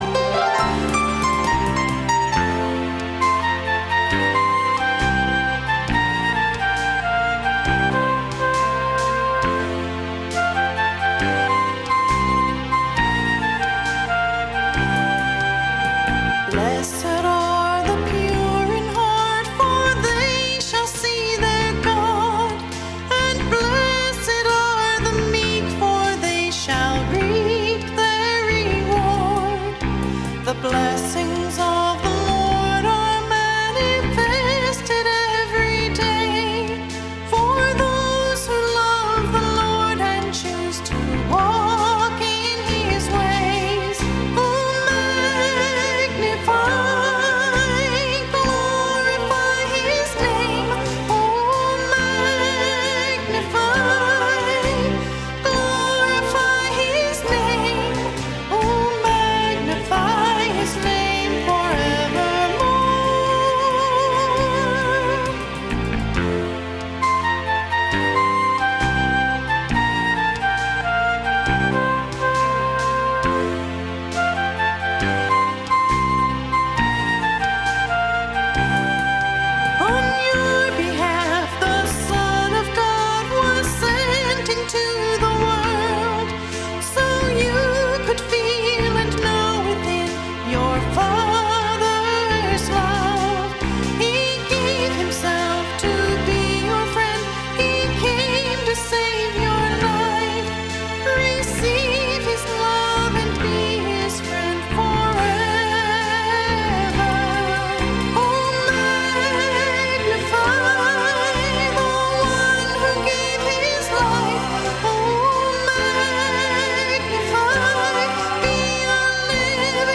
and it turned out to be this song of worship
This was the first album that we recorded in our own studio.